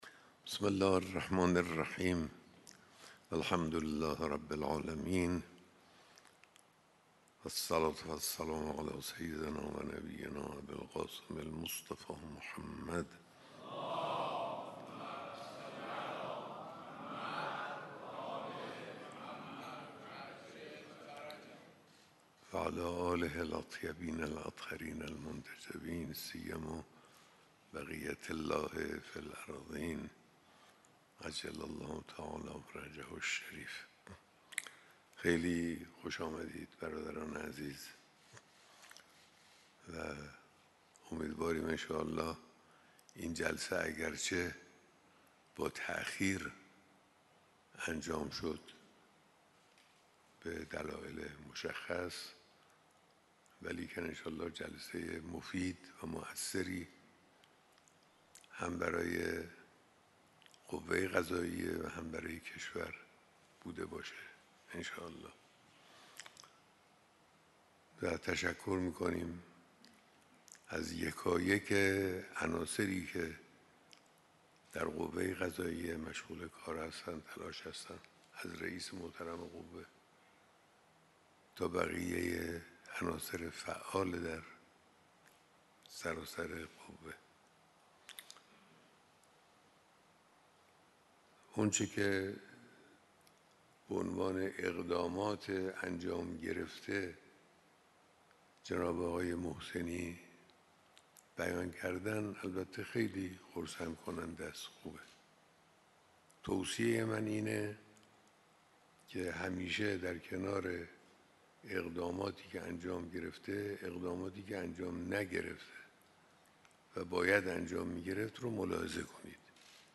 صوت کامل بیانات رهبر انقلاب در دیدار رئیس و مسئولان عالی قوه قضاییه و رؤسای دادگستری‌های سراسر کشور «۲۵ تیر ۱۴۰۴»